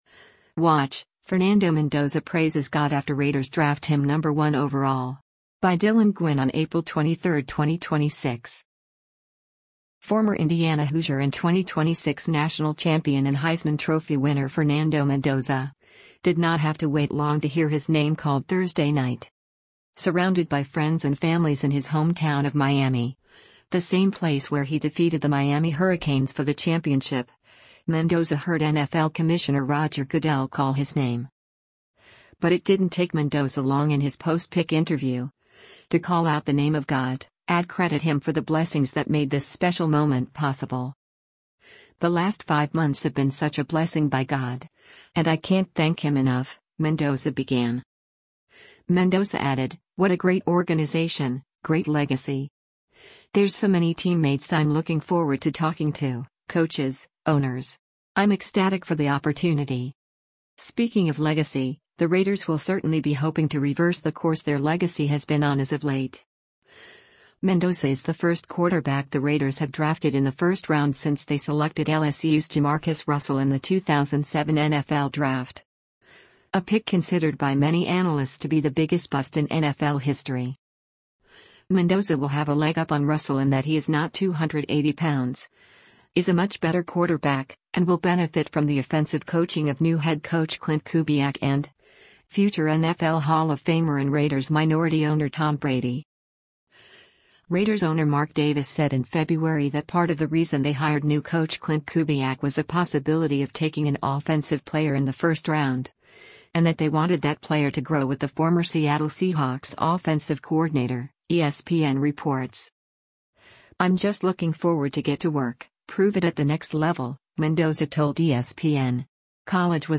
Surrounded by friends and families in his hometown of Miami, the same place where he defeated the Miami Hurricanes for the championship, Mendoza heard NFL Commissioner Roger Goodell call his name.
But it didn’t take Mendoza long in his post-pick interview, to call out the name of God, ad credit Him for the blessings that made this special moment possible.